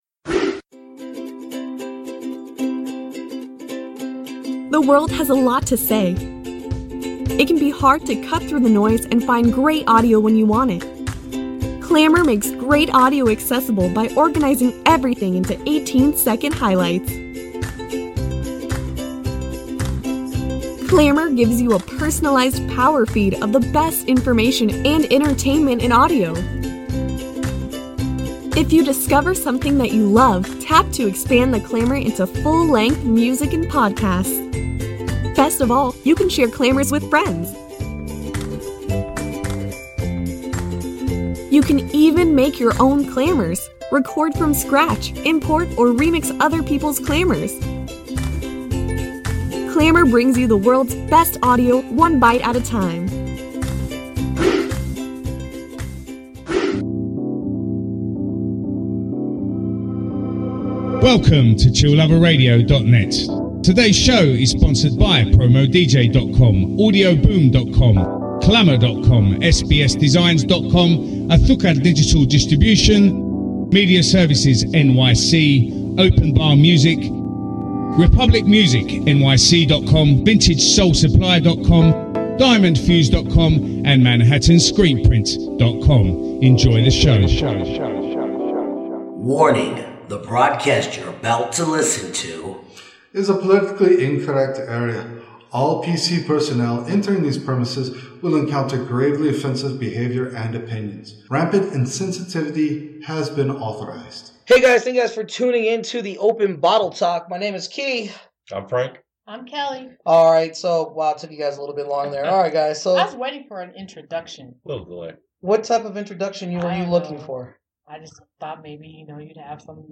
Styles: Beer Talk, Beer News, Beer, Talk Show